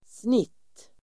Uttal: [snit:]